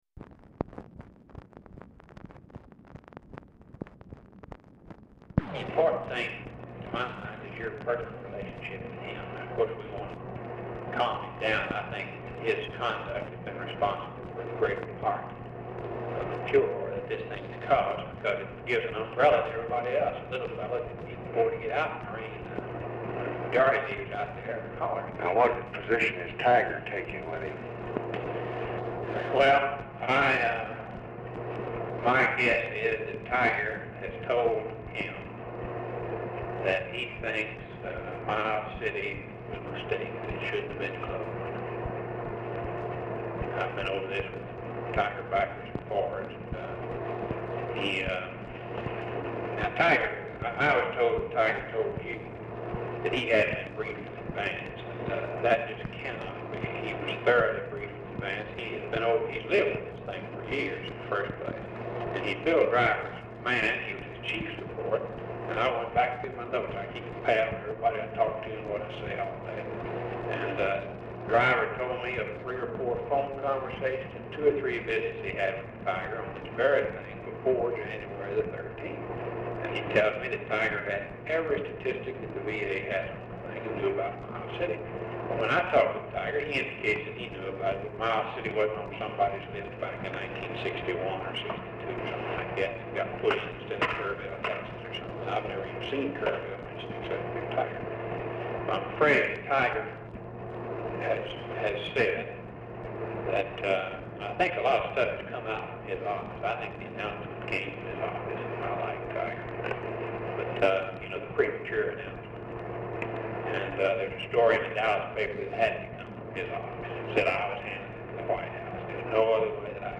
POOR SOUND QUALITY